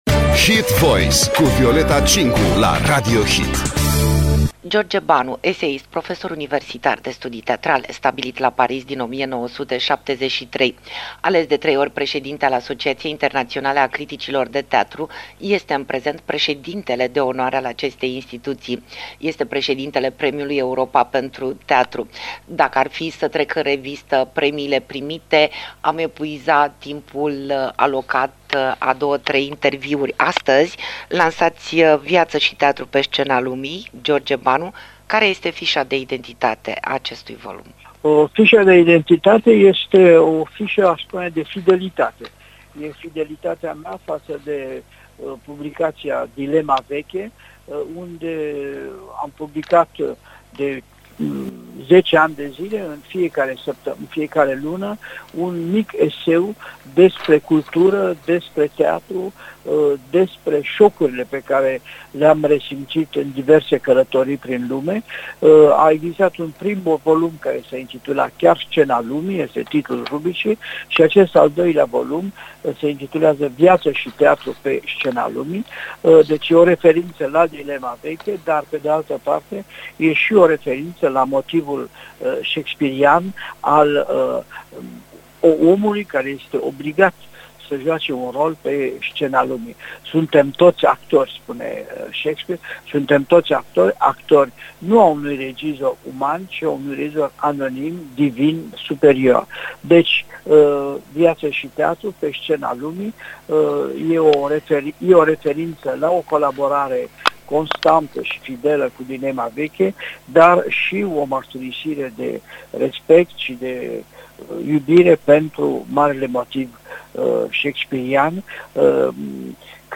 George Banu are o voce caldă. De la Iași la Paris pe scena vieții, la telefon, parcă nu e decât un pas.